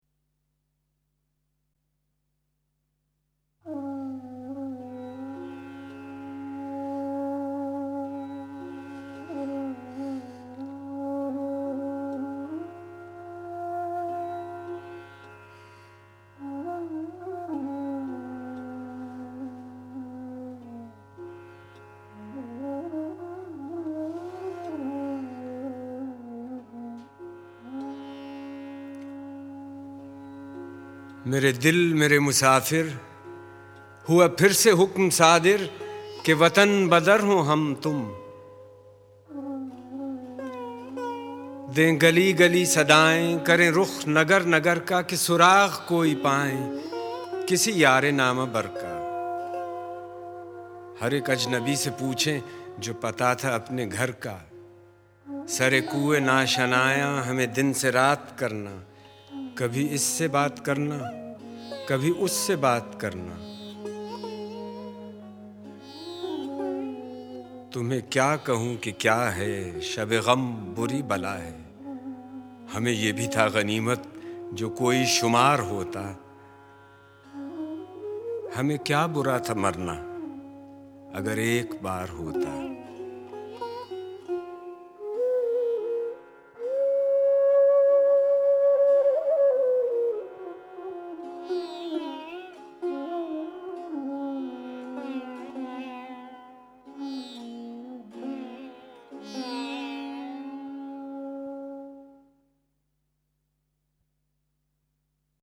Dil-e-Man Musafir Man – Zia Mohyeddin recites Faiz Ahmad Faiz Poetry
Zia Mohyeddin recites Faiz Ahmad Faiz Poetry (Faiz Sahab Ki Mohabbat Mein)